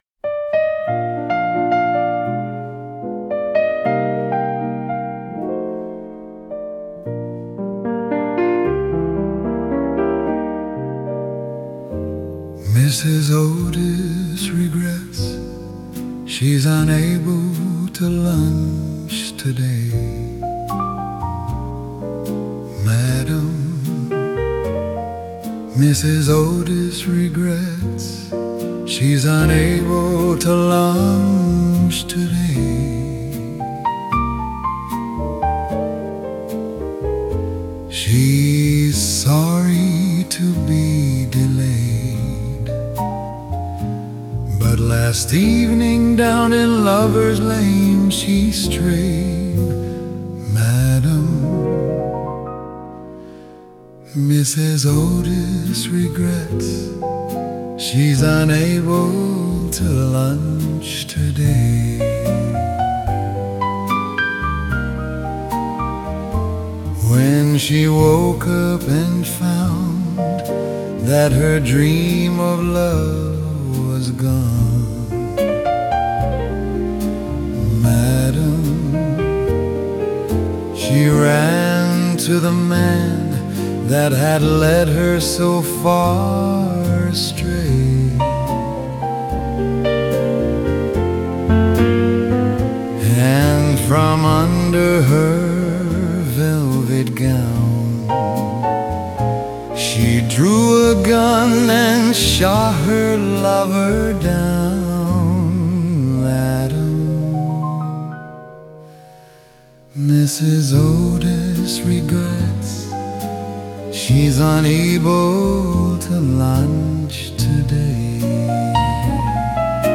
Canciones de muestra con voces clonadas